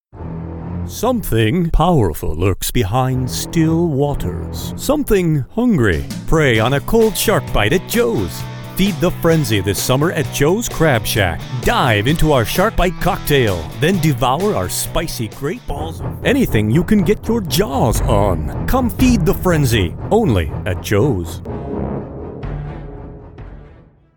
Professionally trained. Pro gear and studio.
Conversational - Personable - Guy Next Door style of voice. Believable and Friendly.
middle west
Sprechprobe: Sonstiges (Muttersprache):